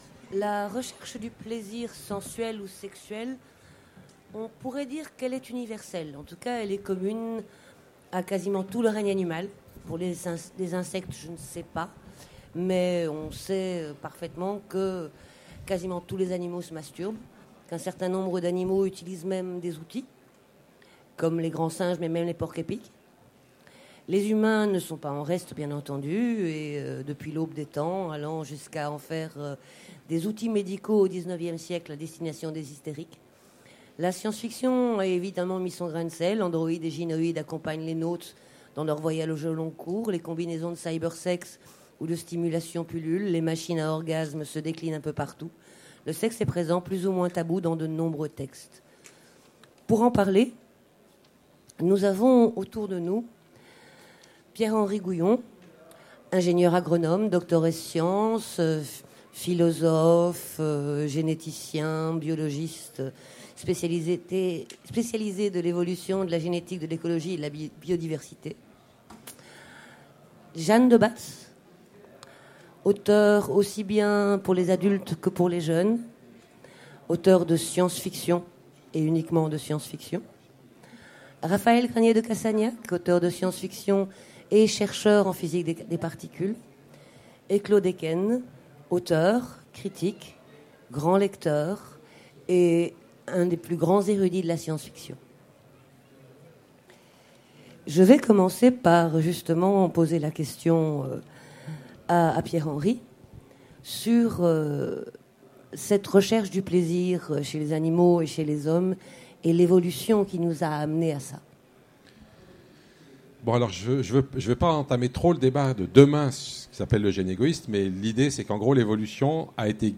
Utopiales 2016 : Conférence Sex machine